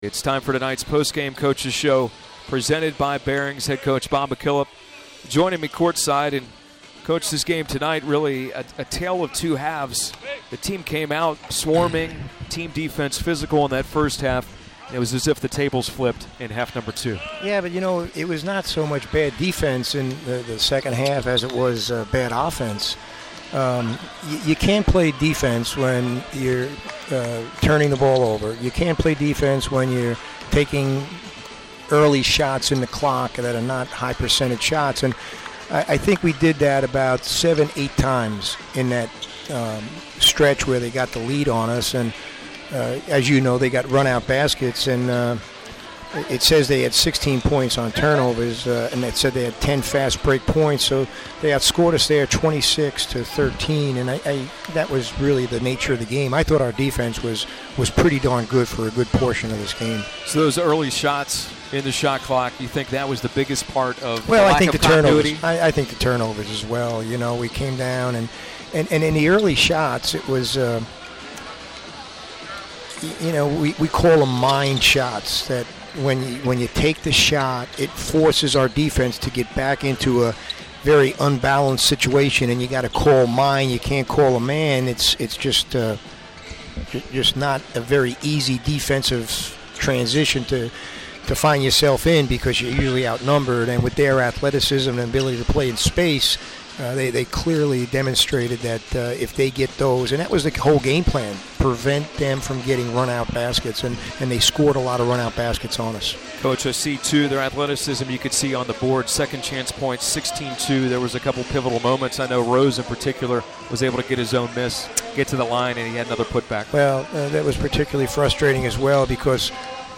McKillop Postgame Interview
McKillop Postgame Temple.mp3